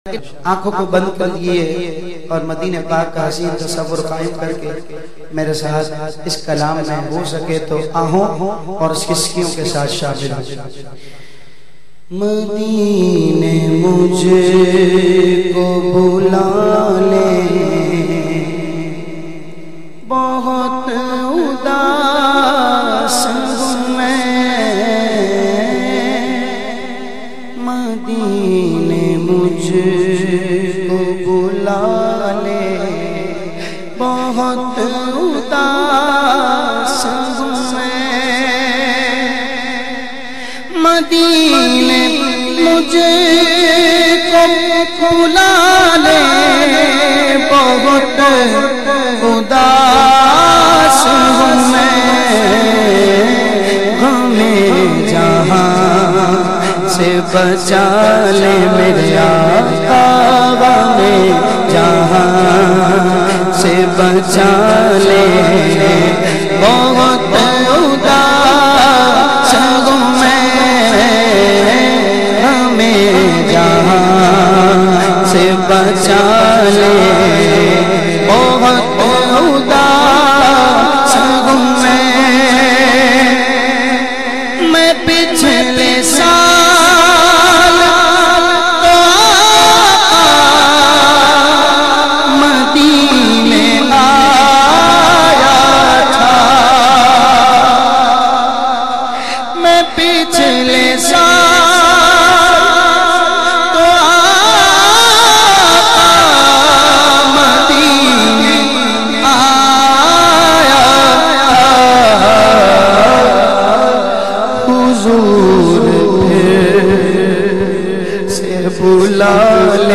in a Heart-Touching Voice
is a distinguished Islamic scholar and naat khawan.